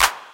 Southside Clapz (8).wav